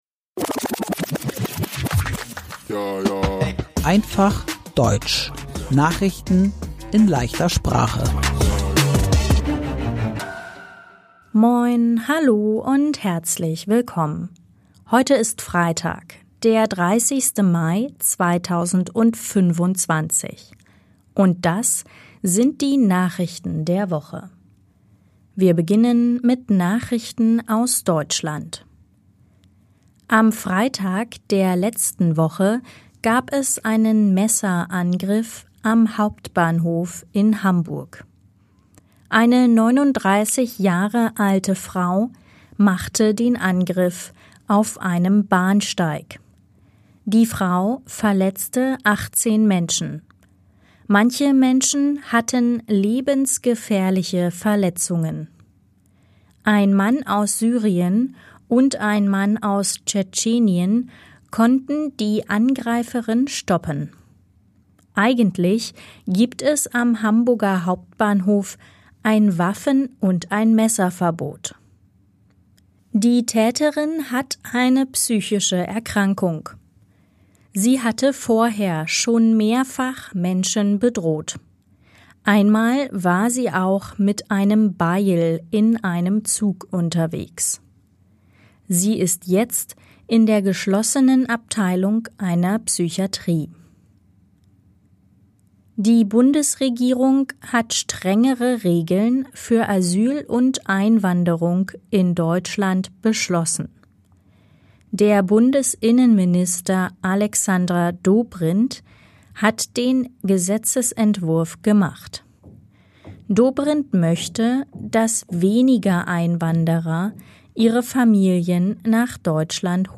Einfach Deutsch: Nachrichten in leichter Sprache